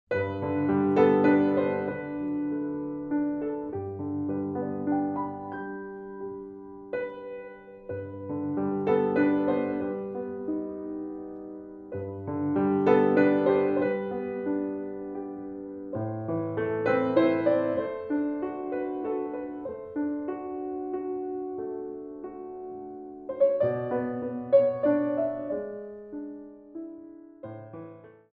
Ballet Music for All Level Classes
Solo Piano
Slow Waltzes